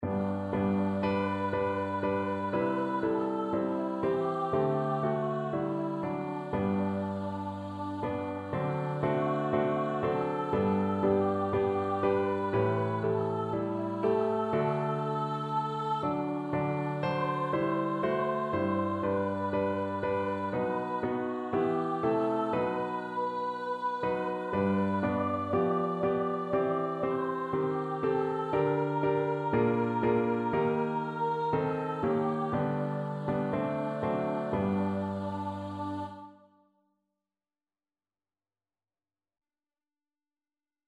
• Singstimme und Klavier [MP3] 638 KB Download